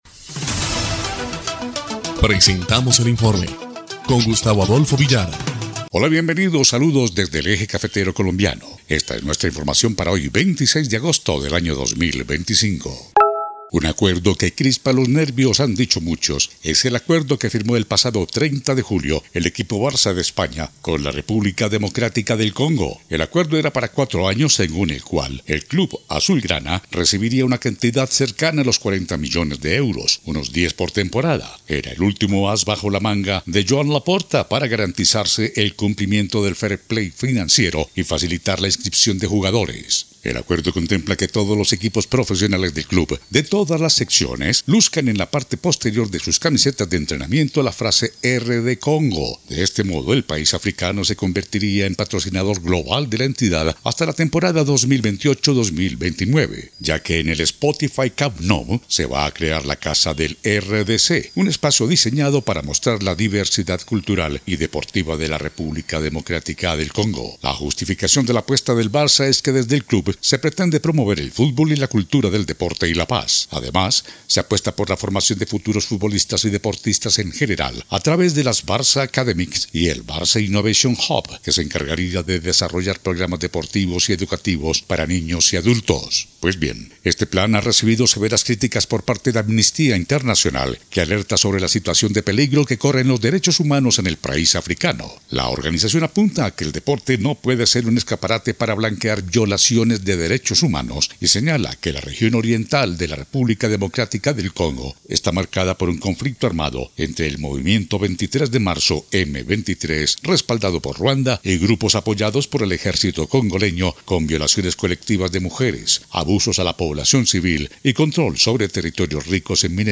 EL INFORME 1° Clip de Noticias del 26 de agosto de 2025